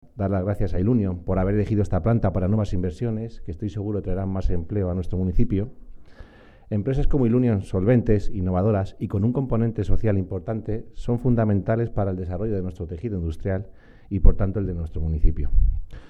Por otro lado, el alcalde de Campo Real agradeció a Ilunion su apuesta la apuesta al desarrollo, la economía y la innovación en la localidad.